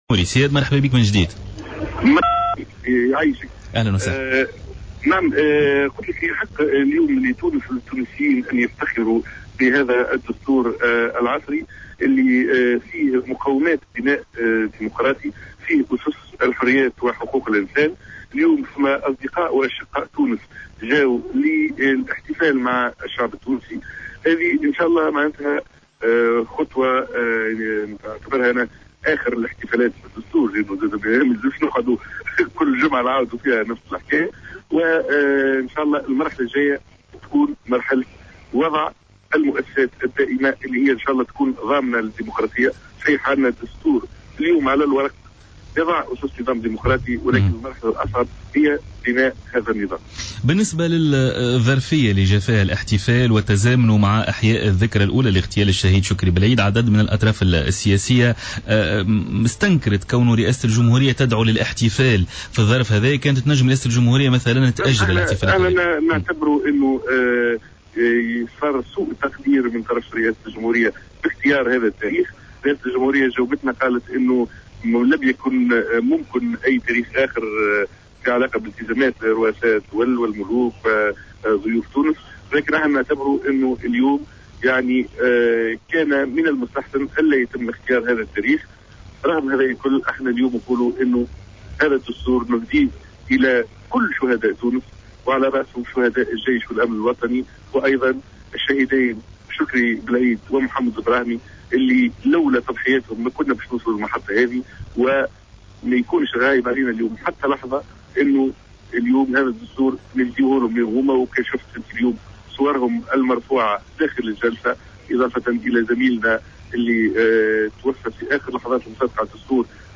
Le député à l’Assemblée nationale constituante (ANC) du parti Républicain, Iyed Dahmani a déclaré vendredi au cours d’une intervention sur les ondes de Jawhara FM, s’est que la présidence de la République ait invité des dirigeants de pays non démocratiques pour assister à la célébration d’un évènement démocratique à savoir l’adoption de la nouvelle Constitution.